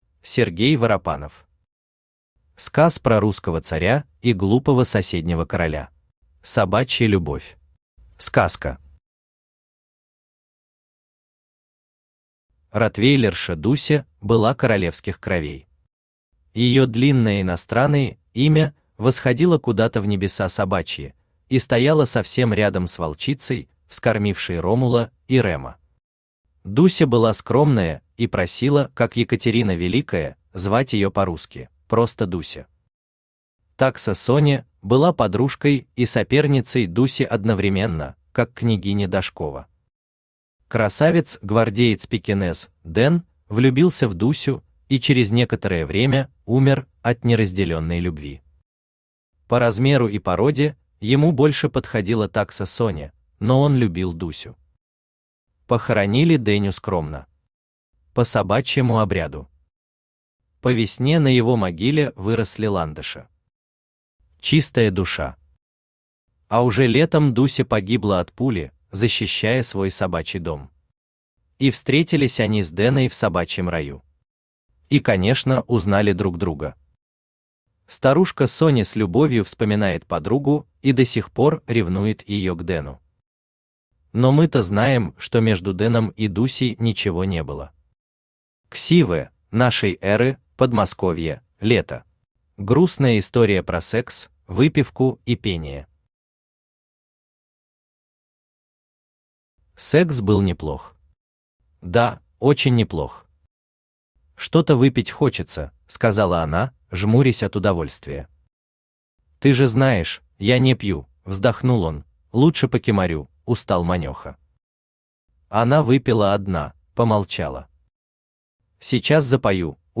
Аудиокнига Сказ про Русского царя и Глупого соседнего короля | Библиотека аудиокниг
Aудиокнига Сказ про Русского царя и Глупого соседнего короля Автор Сергей Алексеевич Воропанов Читает аудиокнигу Авточтец ЛитРес.